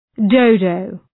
Shkrimi fonetik {‘dəʋdəʋ}